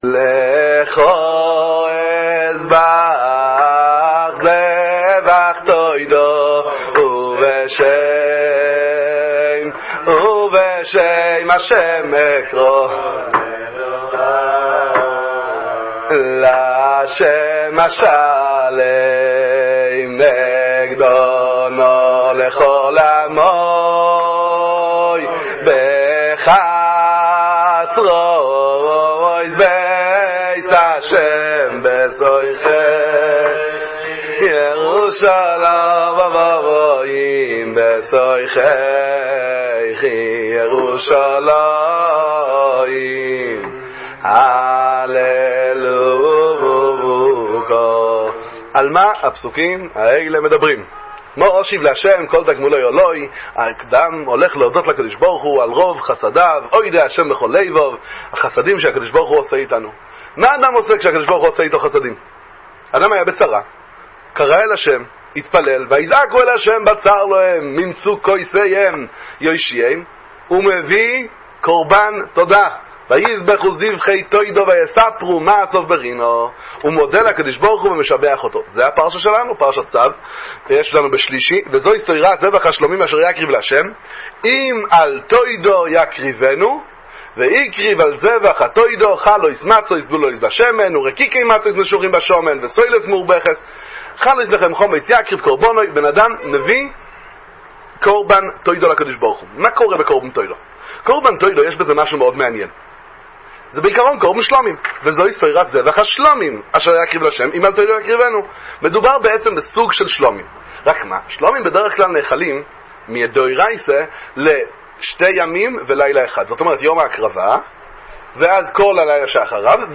אמירת תודה – דבר תורה קצר לפרשת צו